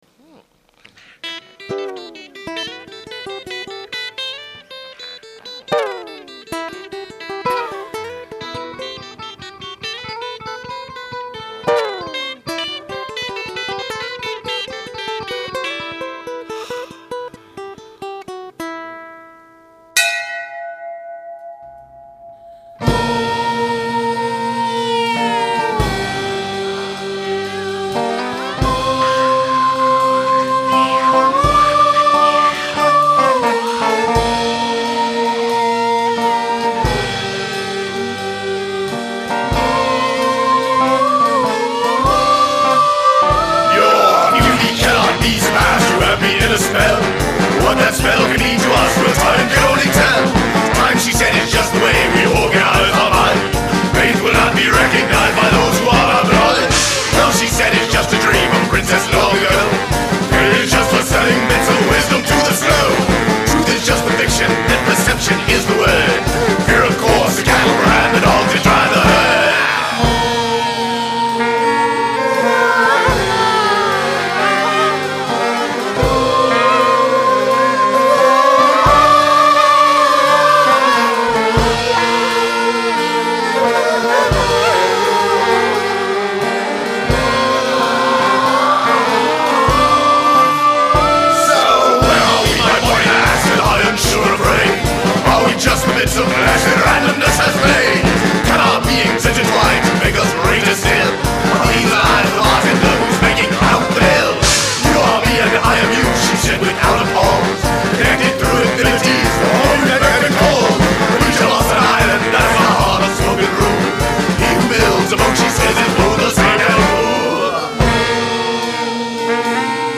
This is the forest dwarf version
it sounded like mad dwarves tromping through the forest